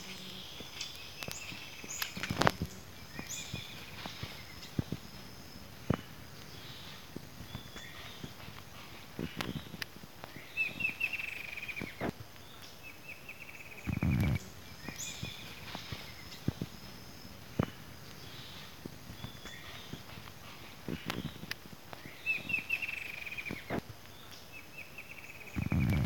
Tiluchi Ala Rojiza (Herpsilochmus rufimarginatus)
22-tiluchi-ala-rojiza-2-5-19.mp3
Nombre en inglés: Rufous-margined Antwren
Localización detallada: Selva Iryapú (600 hectáreas)
Condición: Silvestre
Certeza: Observada, Vocalización Grabada